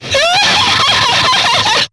Ezekiel-Vox_FX1_jp.wav